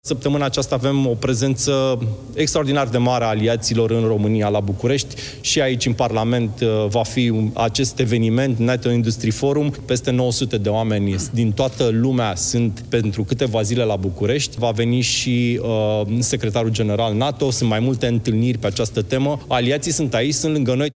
Ministrul Apărării, Ionuț Moșteanu: „Peste 900 de oameni din toată lumea sunt pentru câteva zile la București”